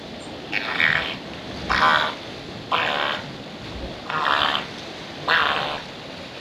Audio files for the following article: Aerial Vocalizations by Wild and Rehabilitating Mediterranean Monk Seals (Monachus monachus) in Greece
Adult Bark
adultbark.wav